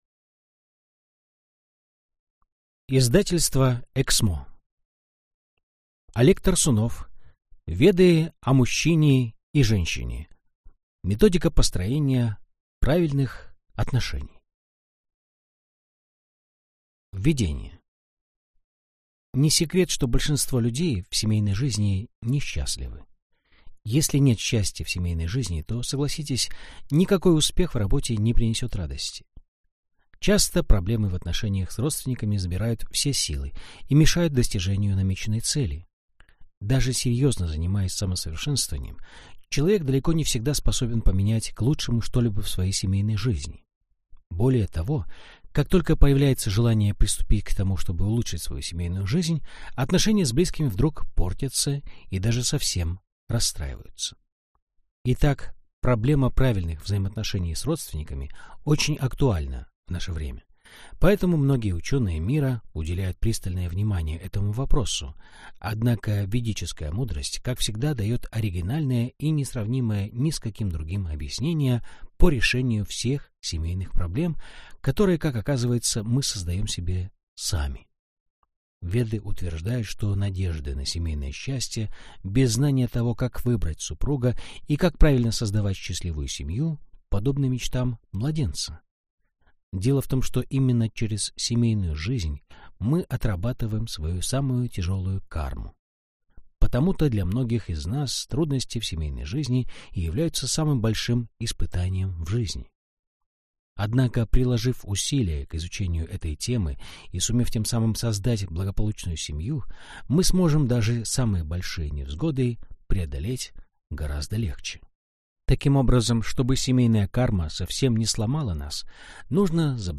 Аудиокнига Веды о мужчине и женщине. Методика построения правильных отношений | Библиотека аудиокниг